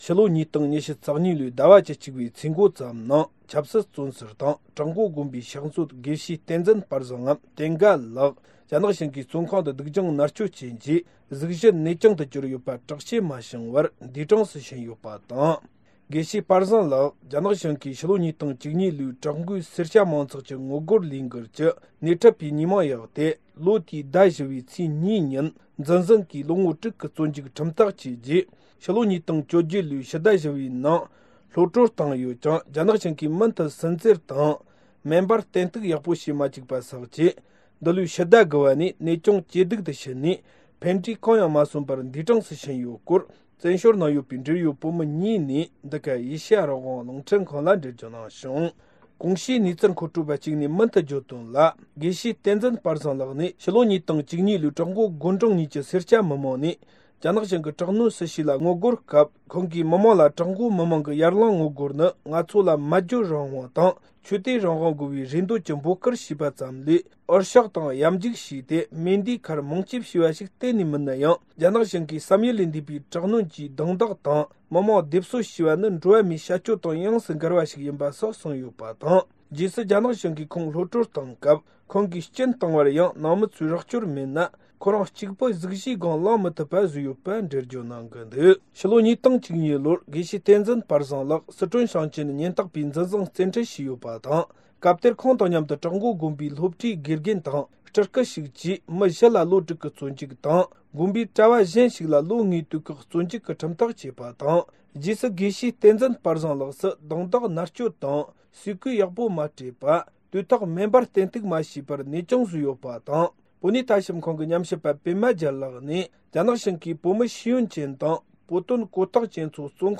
བཙན་བྱོལ་ནང་ཡོད་པའི་འབྲེལ་ཡོད་བོད་མི་གཉིས་ནས་འགྲེལ་བརྗོད་གནང་བྱུང་།